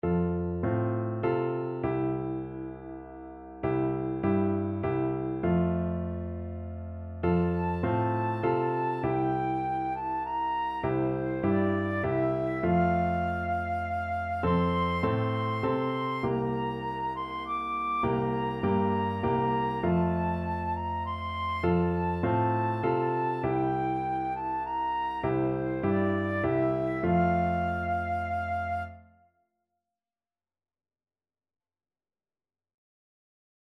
Traditional Trad. Music Alone Shall Live Flute version
3/4 (View more 3/4 Music)
F major (Sounding Pitch) (View more F major Music for Flute )
Traditional (View more Traditional Flute Music)